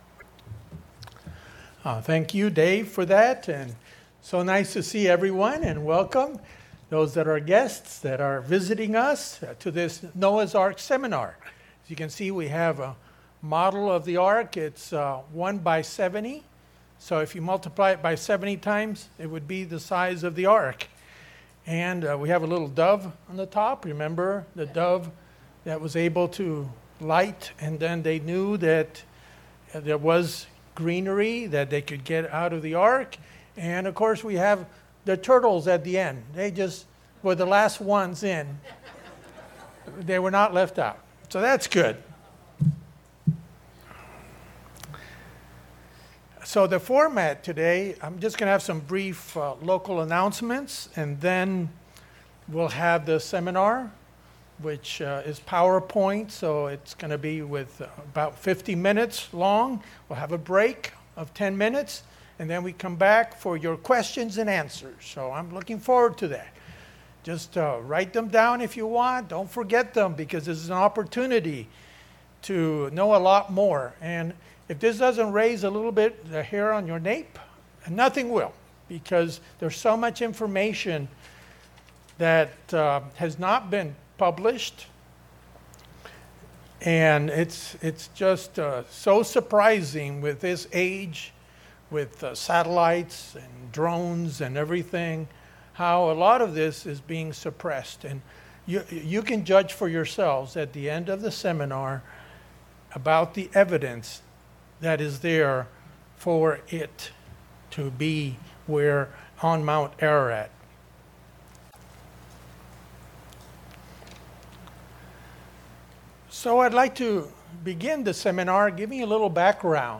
Seminar on the Discovery of Noah's Ark